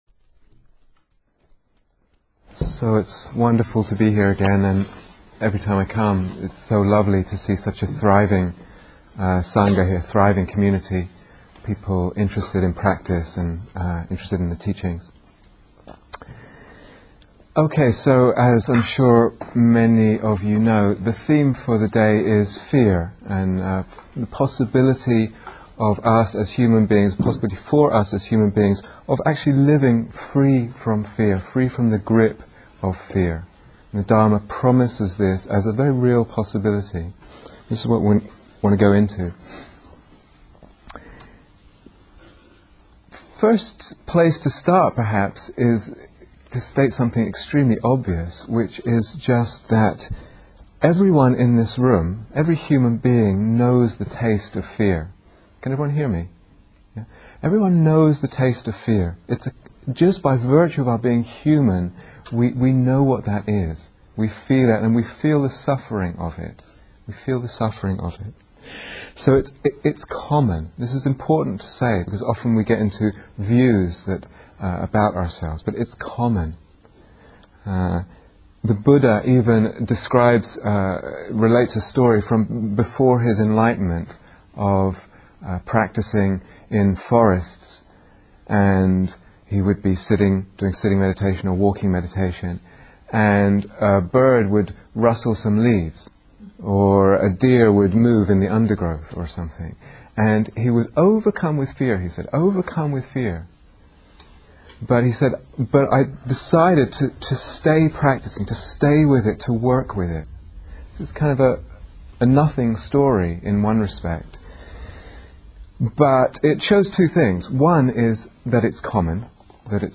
Freedom from Fear and Anxiety (Part One) Download 0:00:00 --:-- Date 7th December 2008 Retreat/Series Day Retreat, London Insight 2008 Transcription So it's wonderful to be here again.